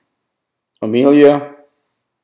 Amelia wakewords from 8 speakers of varying ages, genders and accents.